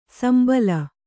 ♪ sambala